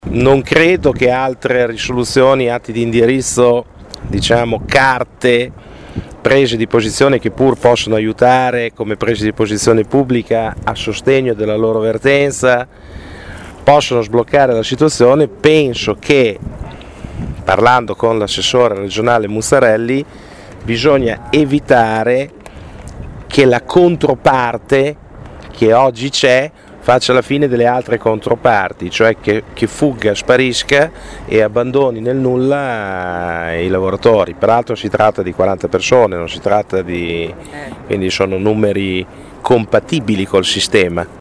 Al loro presidio l’assessore Muzzarelli non si è presentato, ma con i lavoratori, sulle responsabilità dirette dell’assessorato, si è schierato il capogruppo regionale del Pd, Marco Monari, che si è impegnato a sollecitare personalmente Muzzarelli a prendere posizione per risolvere la questione dei lavoratori Bv tech.